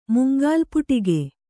♪ mungālpuṭige